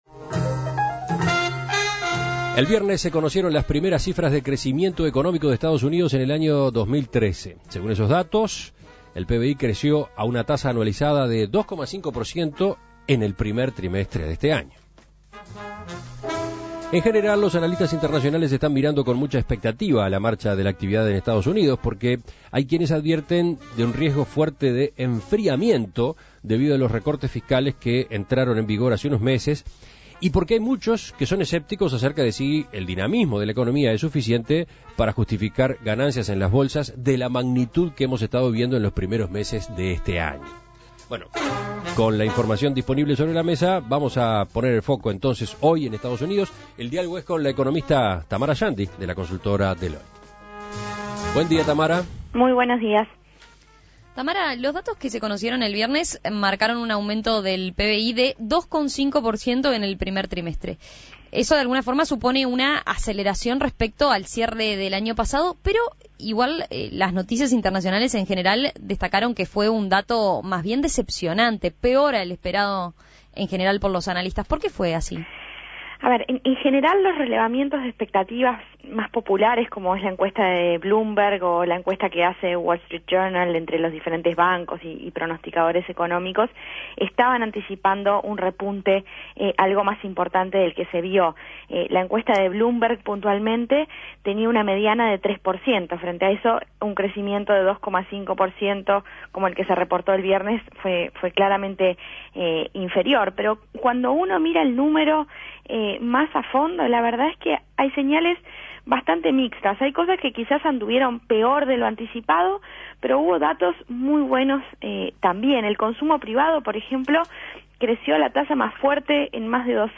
Análisis de la economista